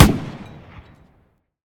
tank-mg-shot-8.ogg